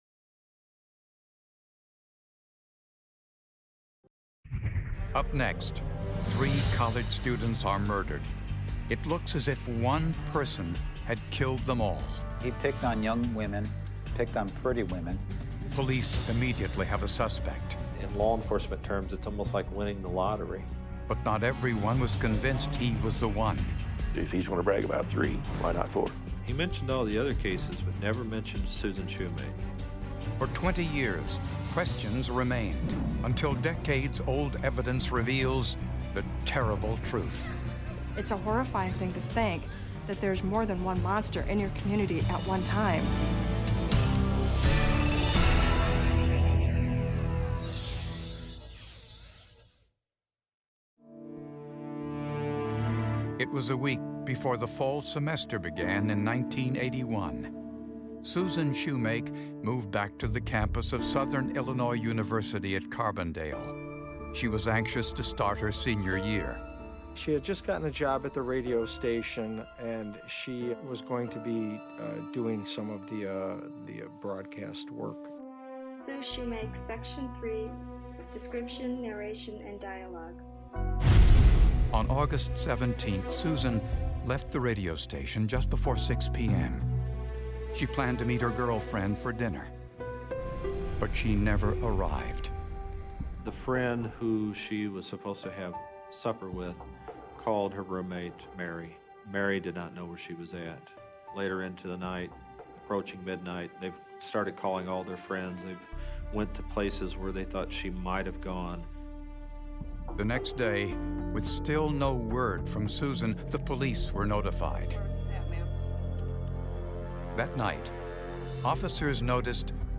All advertisements are strategically placed only at the beginning of each episode, ensuring you can immerse yourself fully in every investigation, every revelation, and every emotional moment without advertising cuts disrupting the flow of our true crime storytelling.